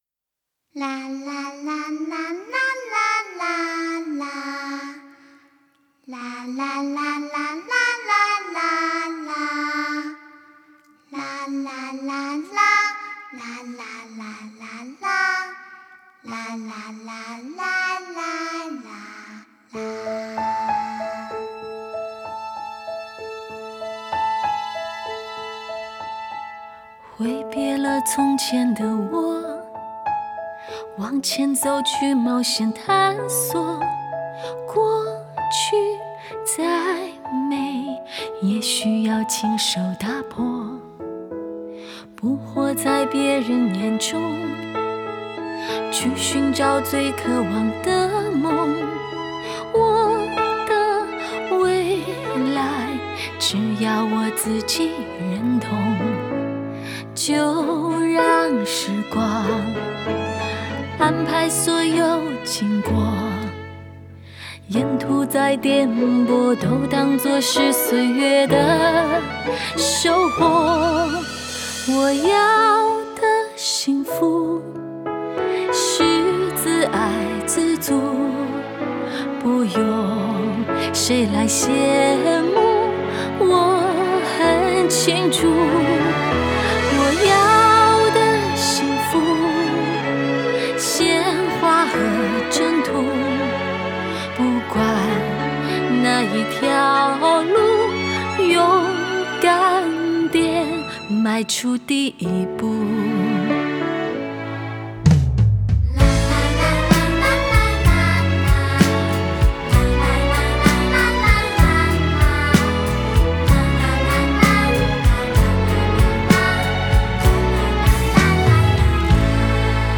吉他
贝斯
鼓
童声
和音
小提琴独奏
弦乐团